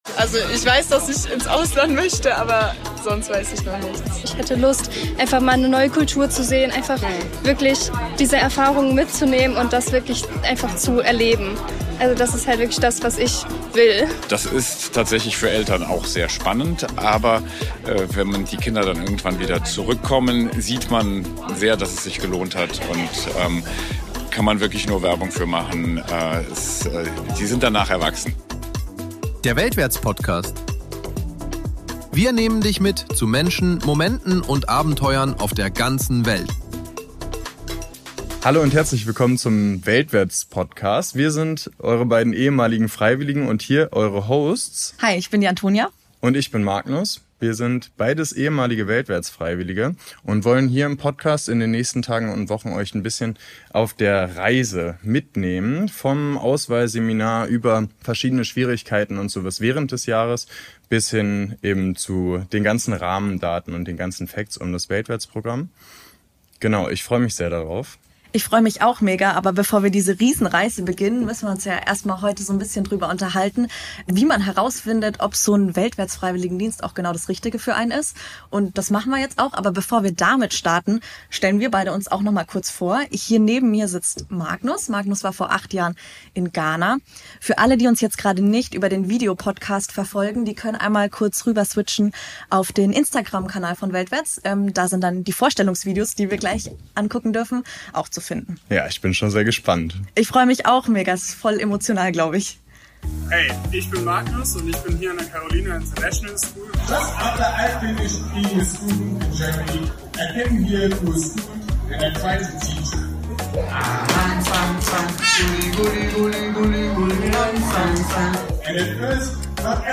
Auf der Go World!-Messe in Siegburg haben wir junge Menschen gefragt, was sie an einem Jahr Auszeit reizt, welche Ängste sie haben und was sie sich davon erhoffen.